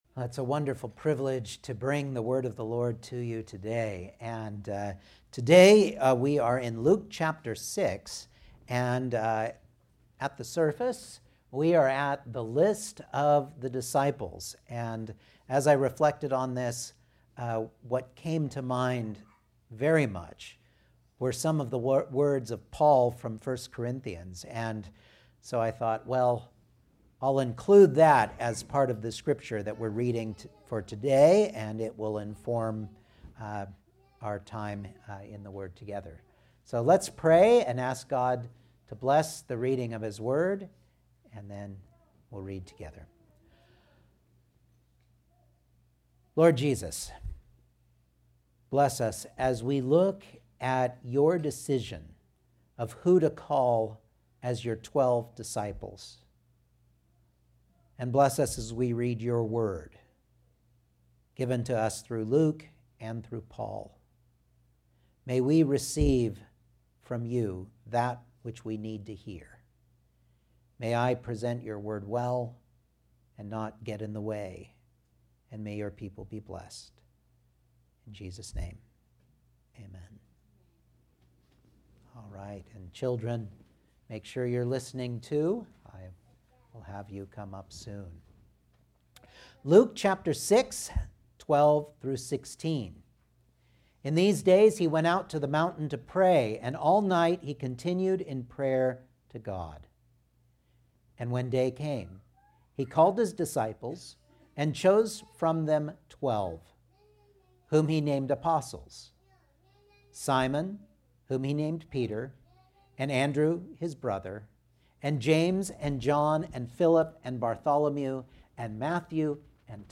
1 Corinthians 1:26-2:5 Service Type: Sunday Morning Outline